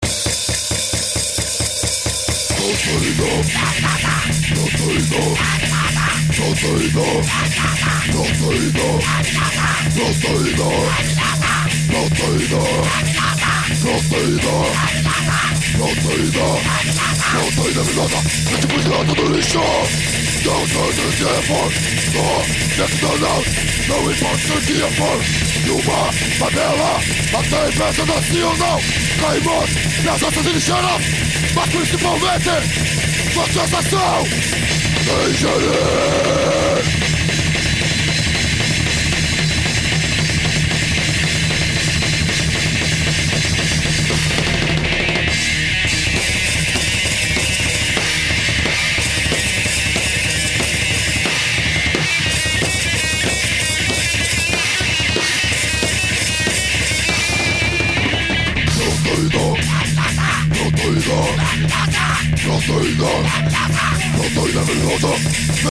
EstiloDeath Metal